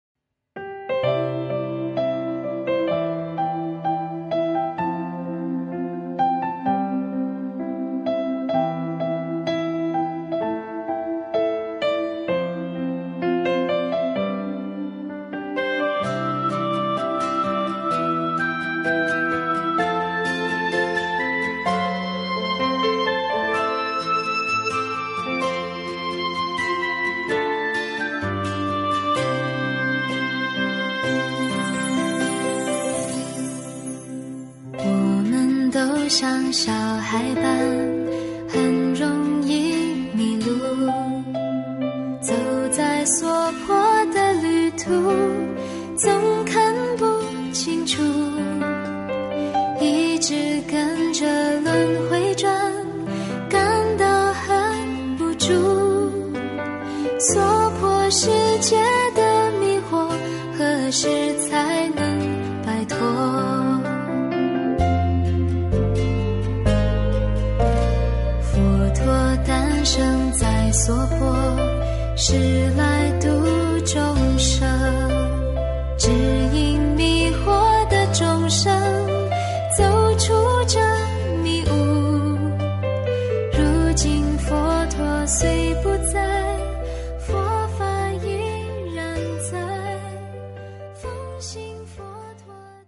音频：丹麦观音堂开光仪式和素食活动的视频！2023年03月06日释迦牟尼佛涅槃日！